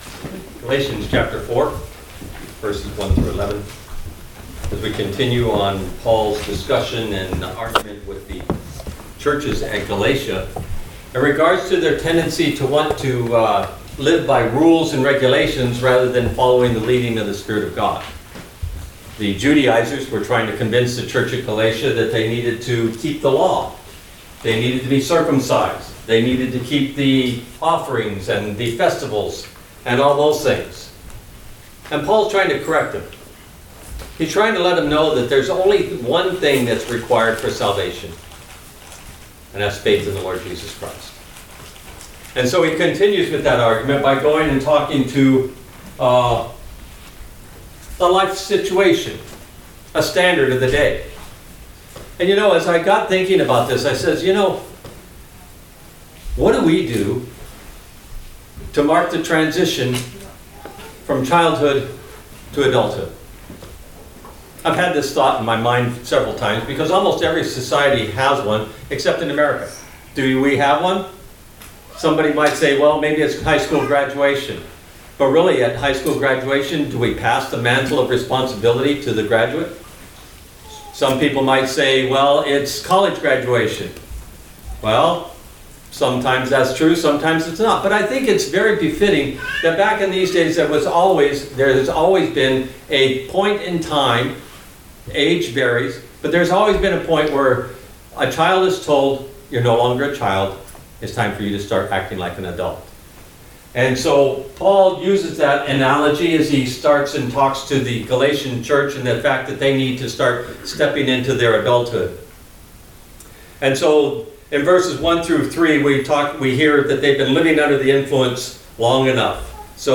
All Sermons Living Like a Child of God Galatians 4:1-11 22 October 2023 Series